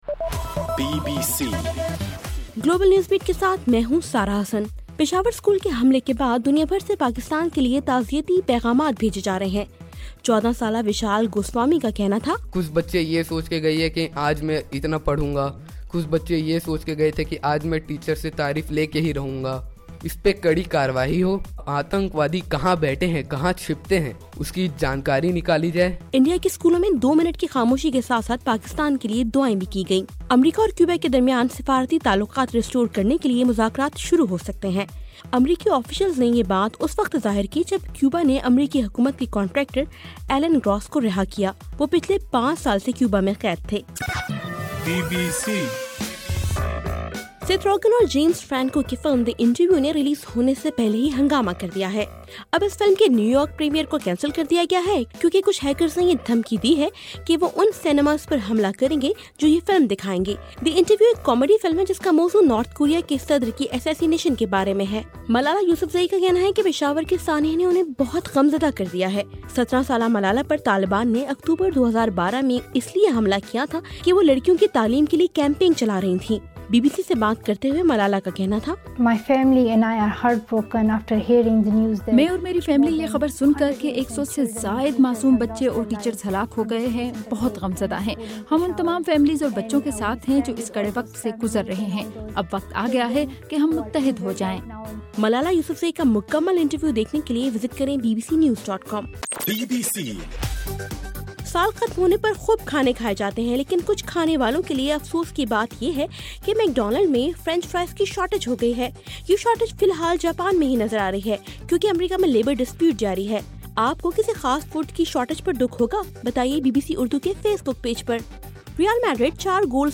دسمبر 17: رات 11 بجے کا گلوبل نیوز بیٹ بُلیٹن